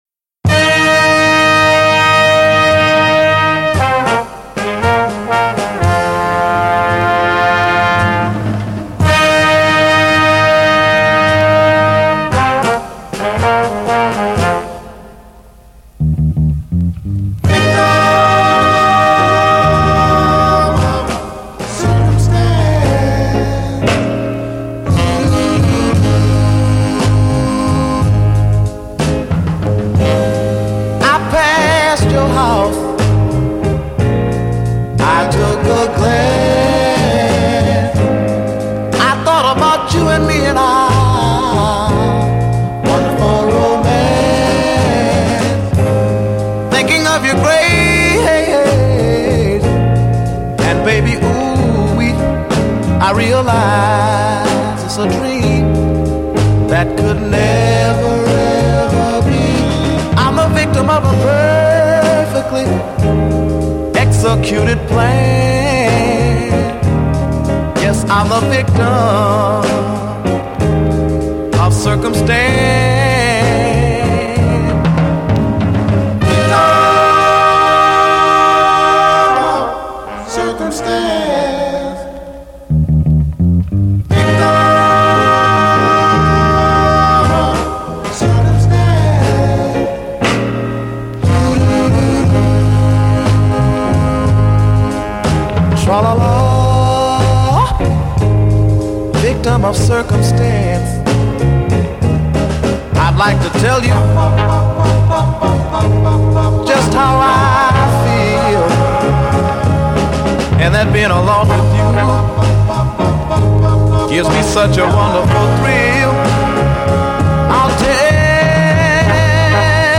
the horns, the singing, the sentiment…just perfect.